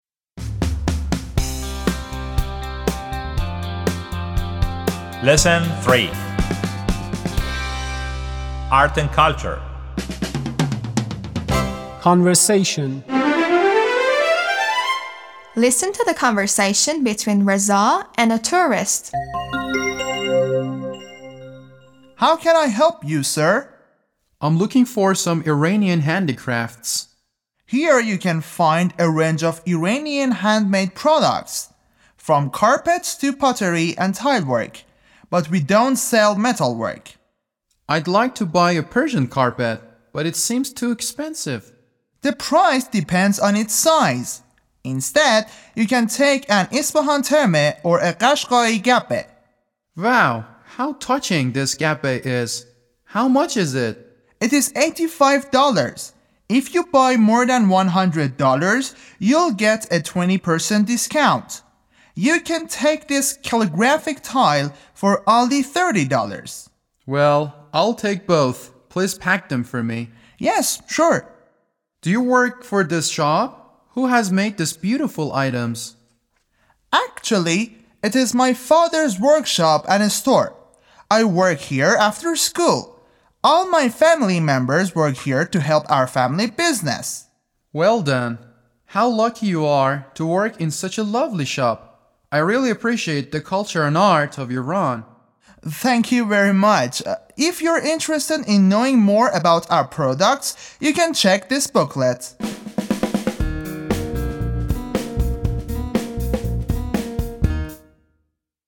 11-L3-Conversation